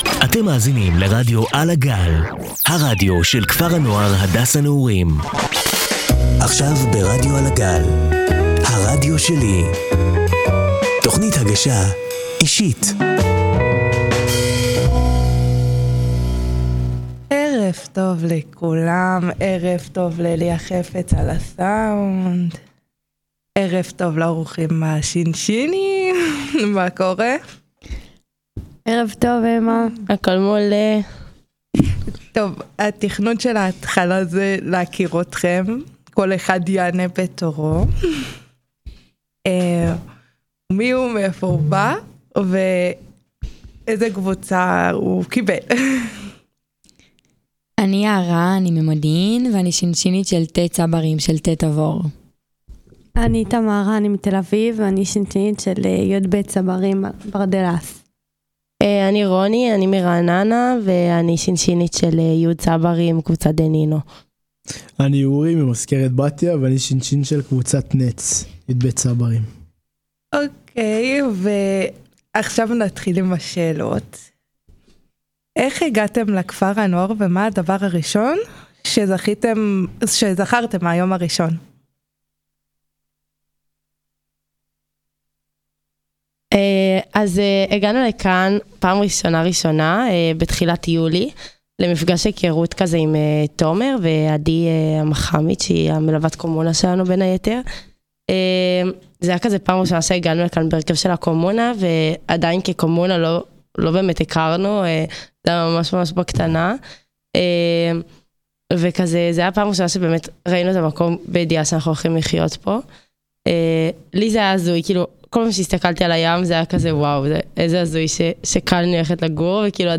בפאנל שינשינים מטורף